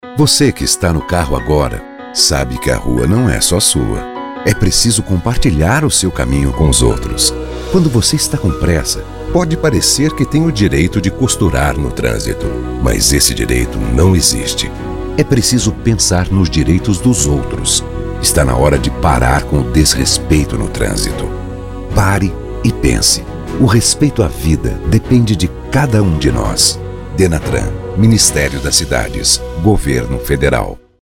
Spots de Rádio